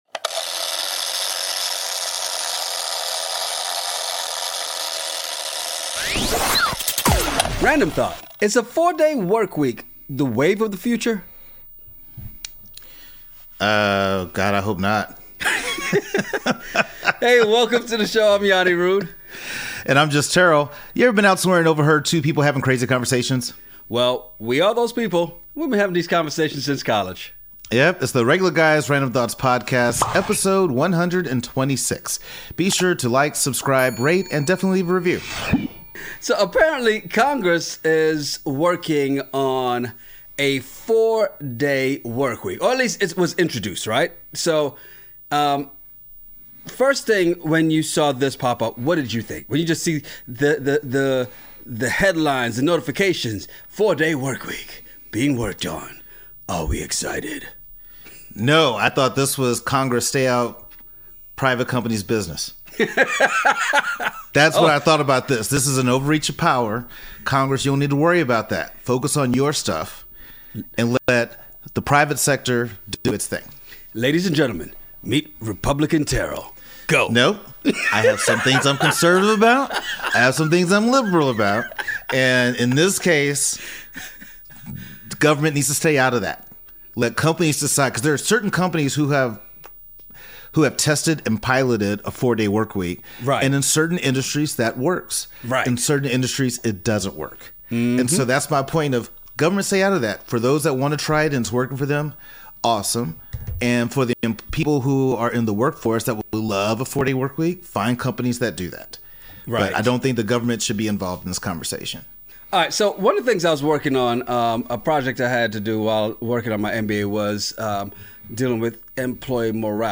Connections are being made and love triangles are forming. Ever been somewhere and overheard two guys having a crazy conversation over random topics?